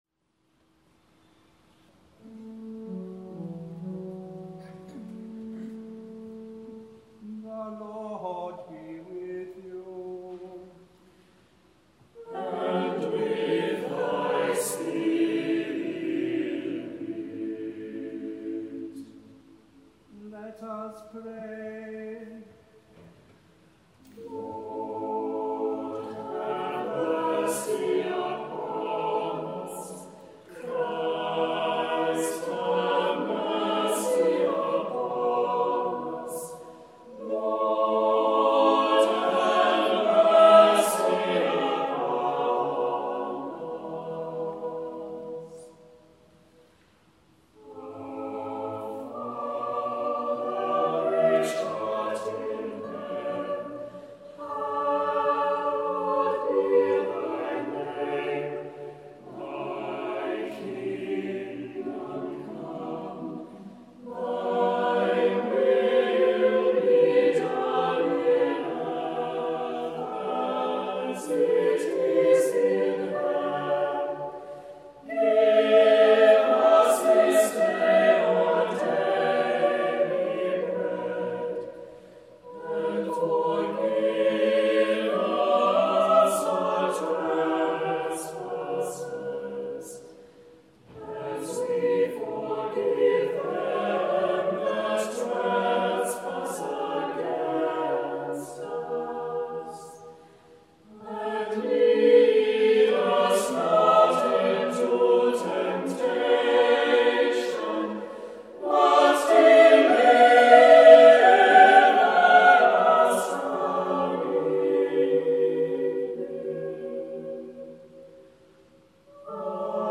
Bernard Rose Responses, sung by the Priory Singers of Belfast at Truro Cathedral